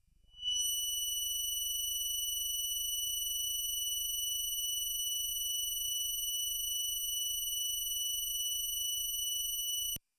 标签： MIDI-速度-97 F6 MIDI音符-90 挡泥板-色度北极星 合成器 单票据 多重采样
声道立体声